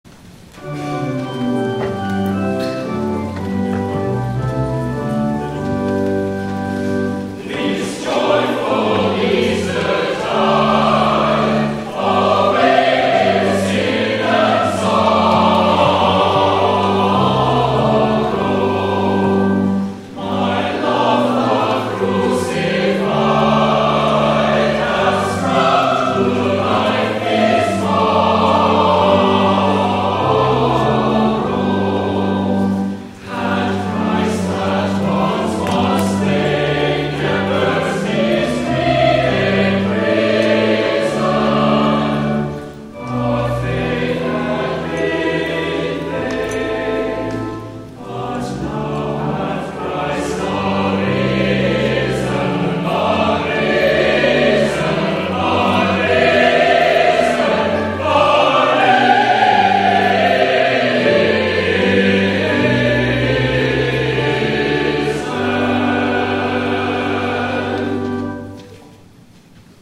Easter Sunday
*THE CHORAL RESPONSE